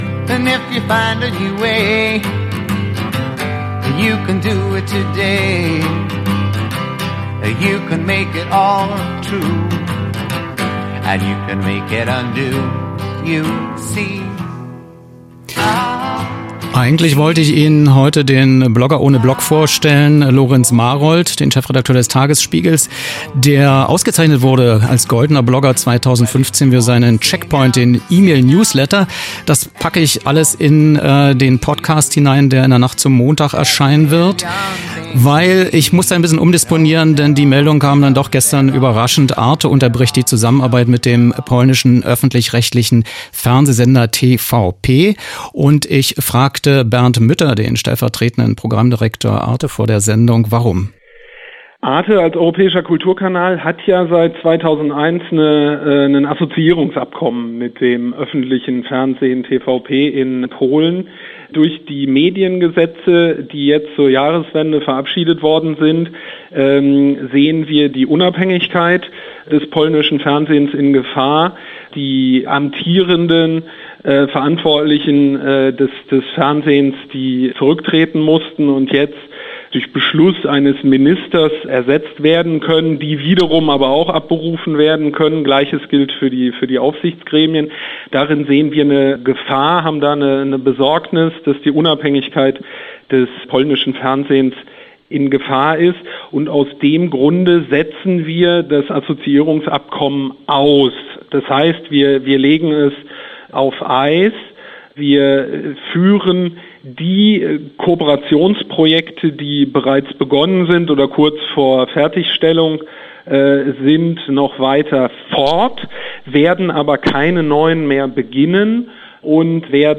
Telefon-Interview